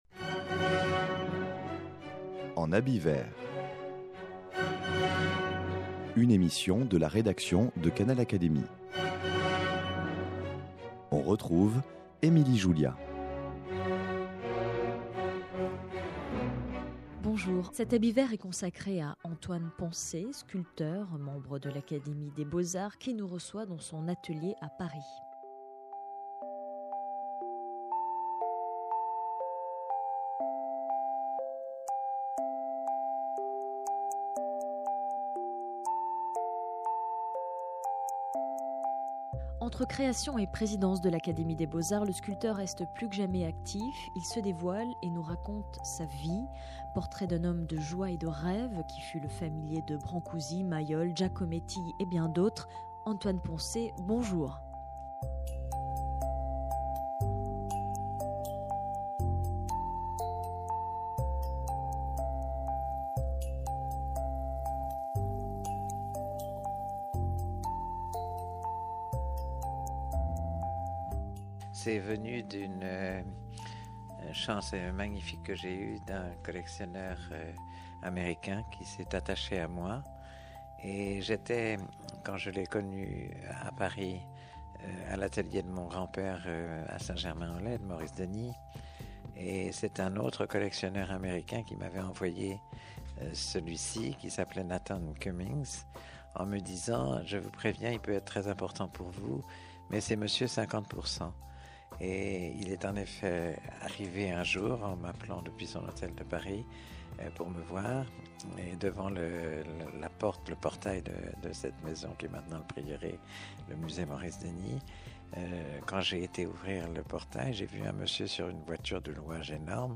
Antoine Poncet nous reçoit dans son atelier à Paris.